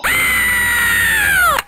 Worms speechbanks
ouch.wav